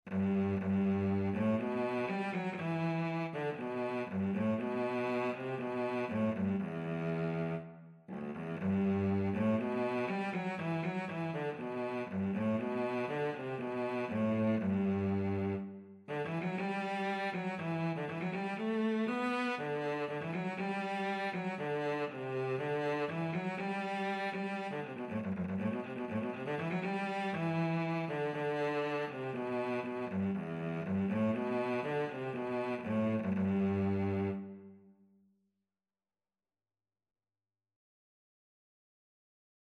Traditional Music of unknown author.
G major (Sounding Pitch) (View more G major Music for Cello )
Cello  (View more Intermediate Cello Music)
Traditional (View more Traditional Cello Music)